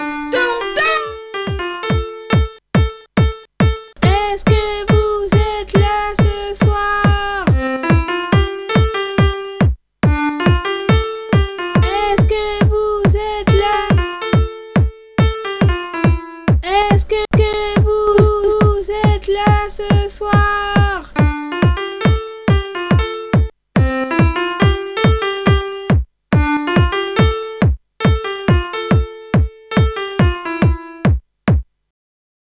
Objet oeuvre musique MUSIQUE TECHNO